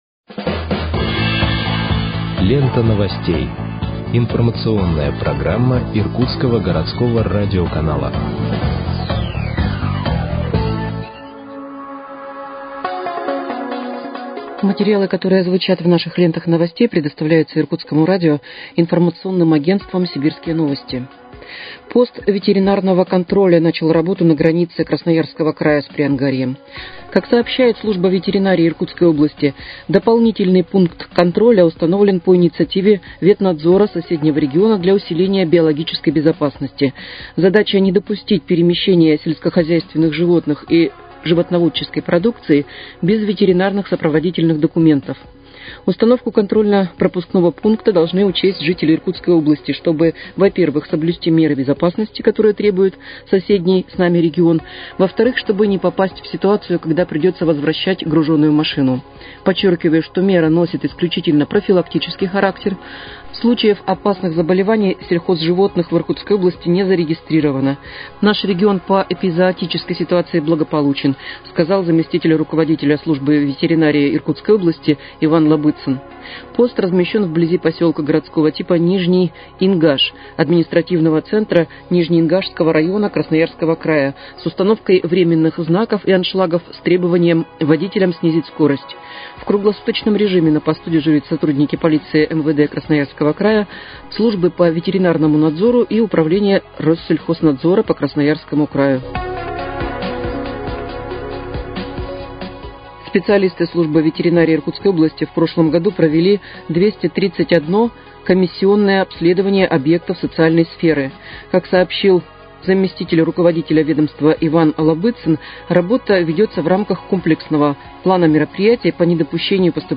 Выпуск новостей в подкастах газеты «Иркутск» от 4.02.2026 № 1